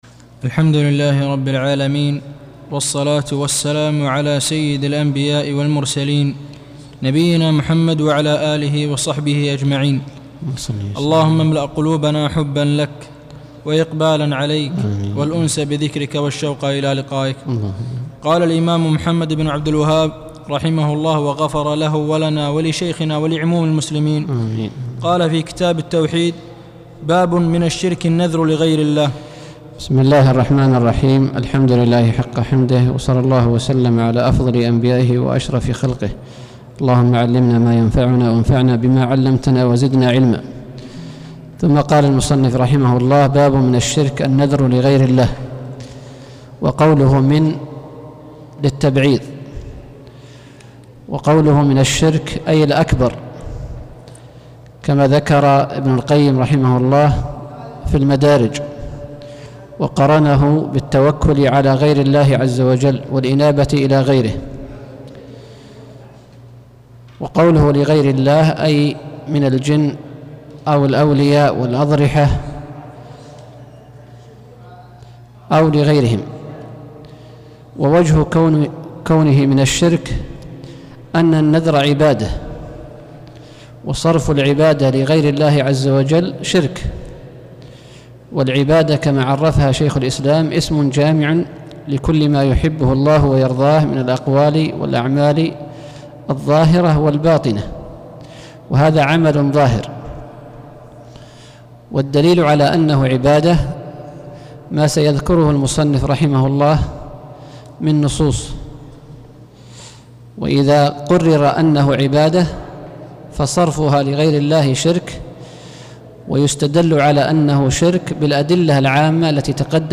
الدرس الثالث عشر: باب من الشرك النذر لغير الله